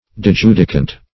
Search Result for " dijudicant" : The Collaborative International Dictionary of English v.0.48: Dijudicant \Di*ju"di*cant\, n. [L. dijudicans, p. pr.] One who dijudicates.